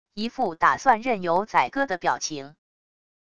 一副打算任由宰割的表情wav音频生成系统WAV Audio Player